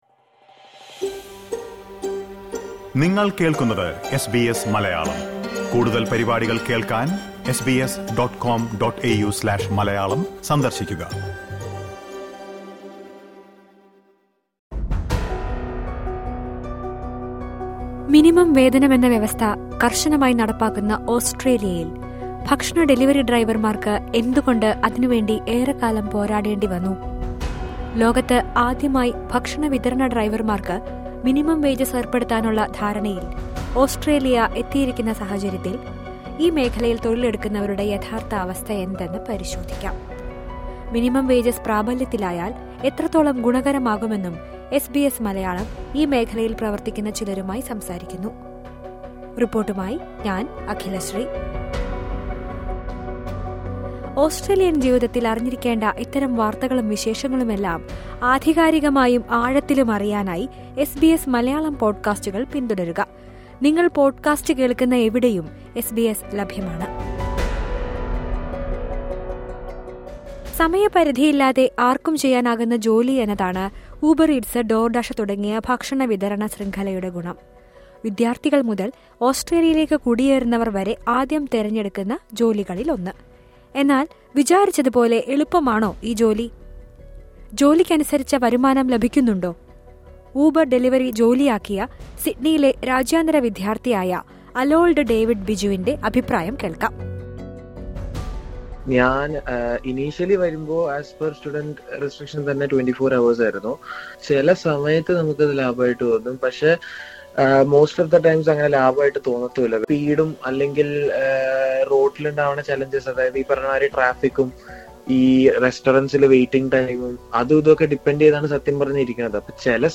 എന്നാൽ, പലപ്പോഴും മിനിമം വേജസ് പോലും ലഭിക്കാത്ത ഈ തൊഴിൽ മേഖലയിലെ വെല്ലുവിളികൾ എന്തൊക്കെയാണ്. ഈ മേഖലയിൽ ജോലി ചെയ്യുന്ന ചിലരുടെ അഭിപ്രായങ്ങൾ കേൾക്കാം, മുകളിലെ പ്ലേയറിൽ നിന്ന്...